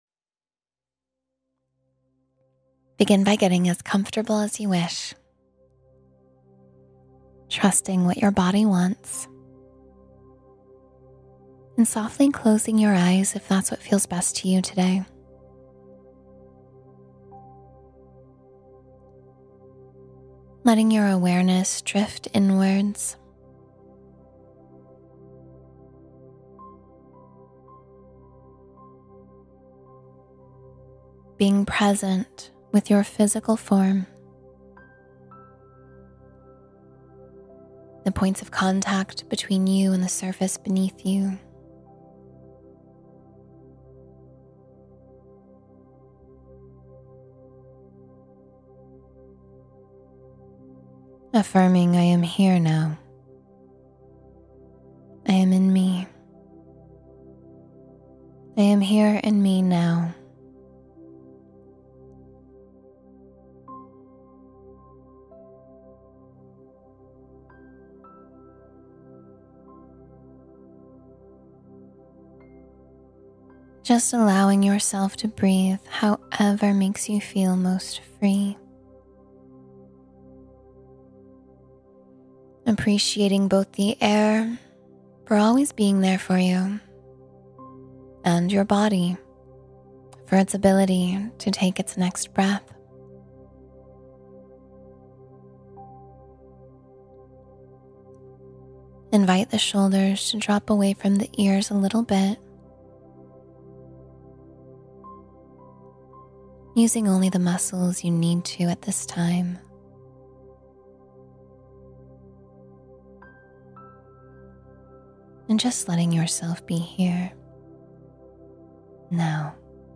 Enter 20 Minutes of Inner Stillness — Meditation for Profound Peace